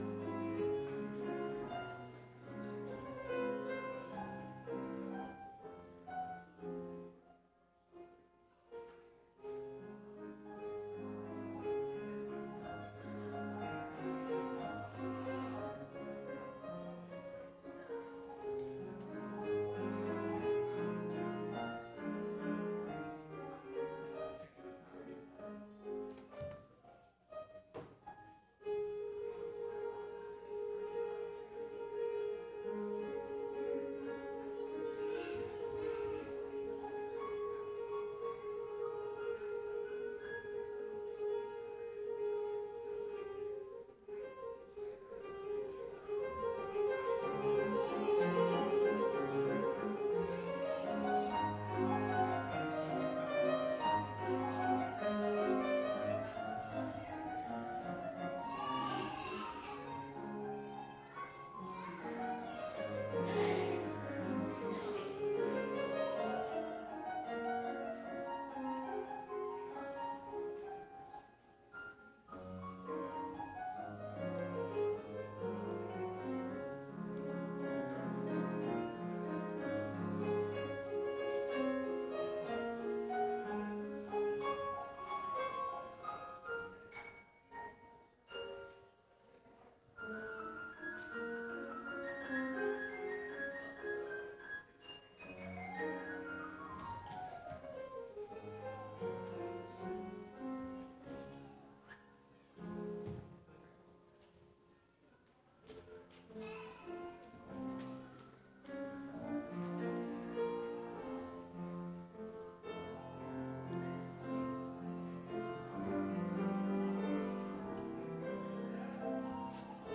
Calvin Christian Reformed Church Sermons
ORDER OF WORSHIP